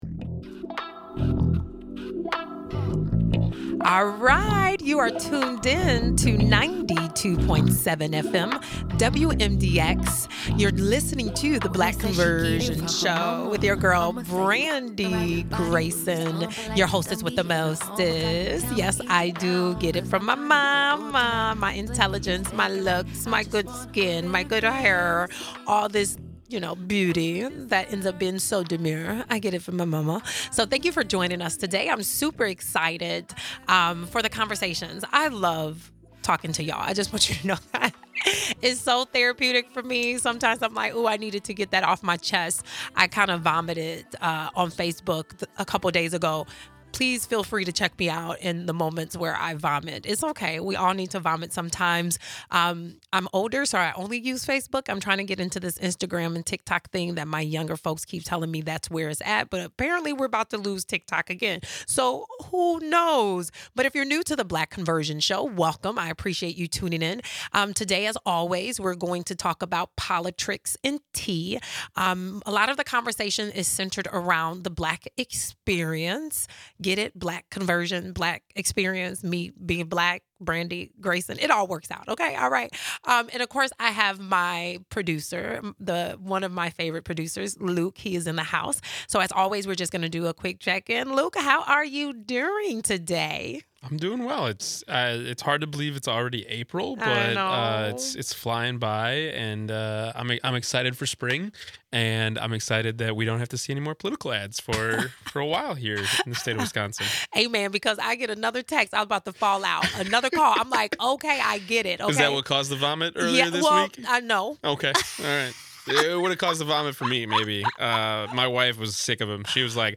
The show then shares clips of how similar instances can happen even to elected officials who show up in places that are not traditionally created for strong, black women, like Congresswoman Jasmine Crockett (D-TX).